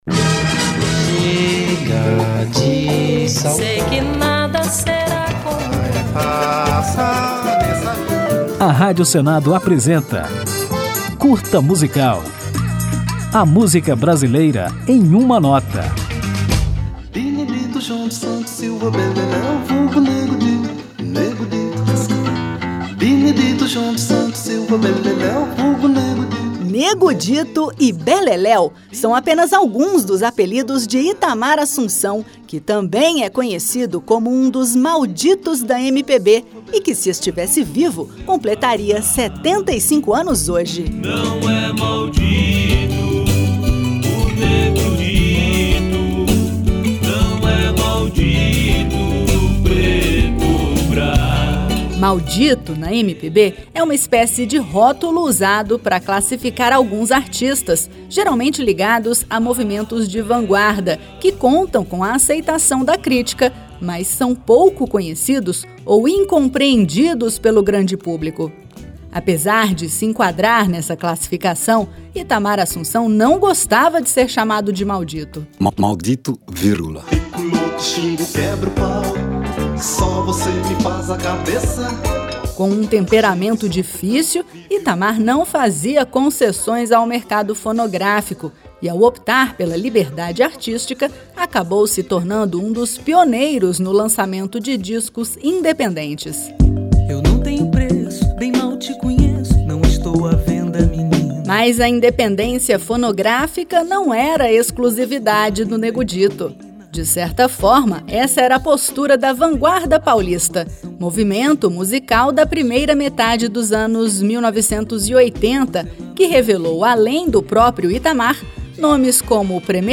Para marcar a data, o Curta Musical preparou um programa sobre este ícone da Vanguarda Paulista, que integra o seleto rol dos "Malditos" da MPB. Depois de conhecermos um pouco da trajetória do músico, ouviremos um dos clássicos de Itamar Assumpção, a canção Sampa Midnight.